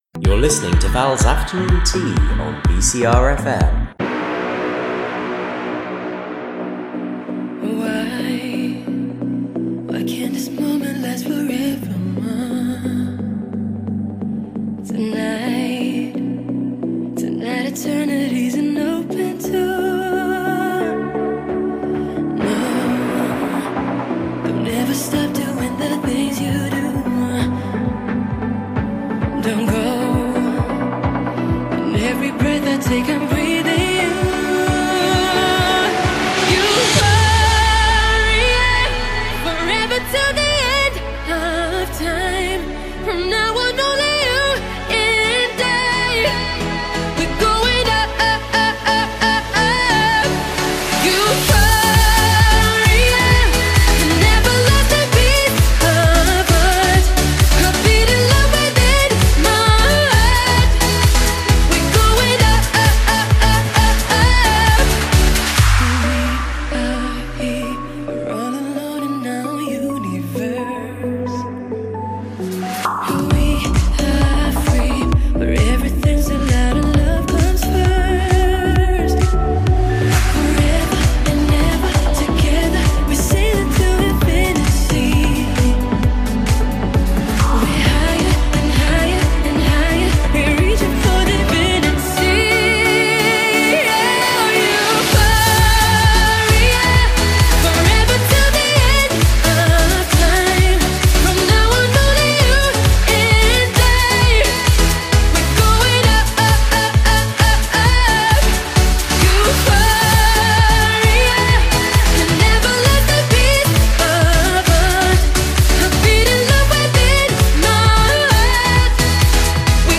If you missed my radio show on BCRFM you can listen back here. It's a Eurovision special, so I'm playing some of my favourite Eurovision songs and telling you about some of the things you can do in Mayo.